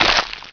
flakhit.wav